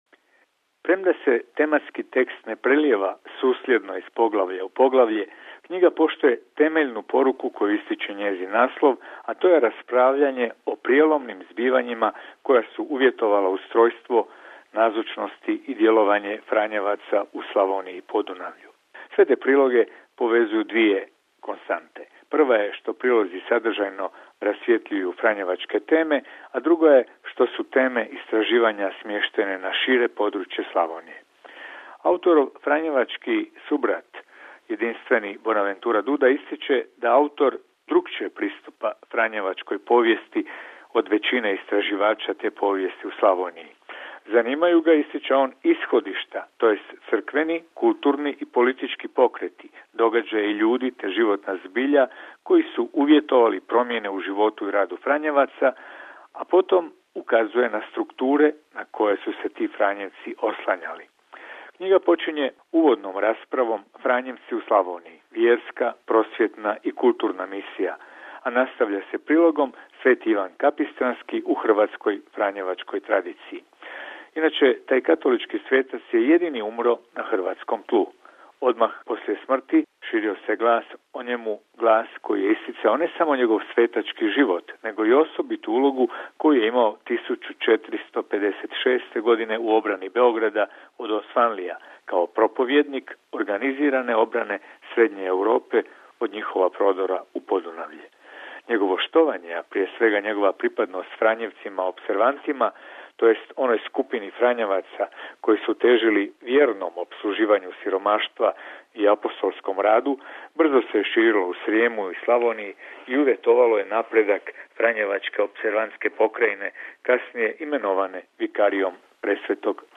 Recenzija knjige